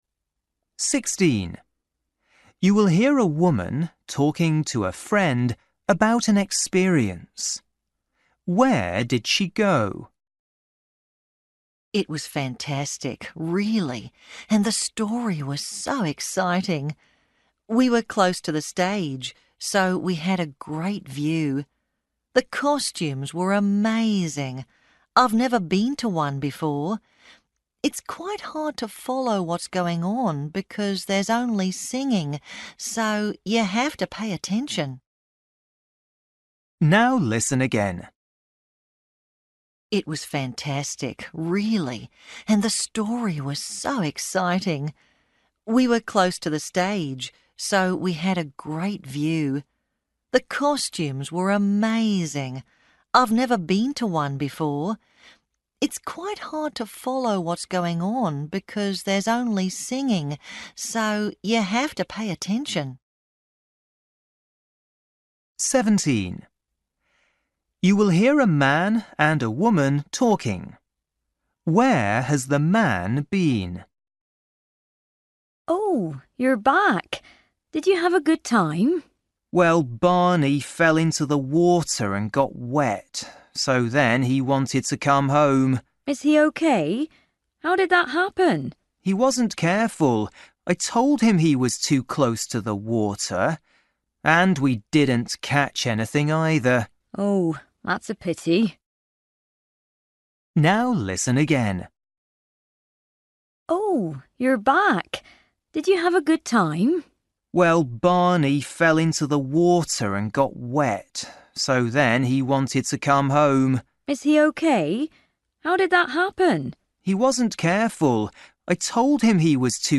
Listening: everyday short conversations
16   You will hear a woman talking to a friend about an experience.
17   You will hear a man and a woman talking.
19   You will hear two friends talking.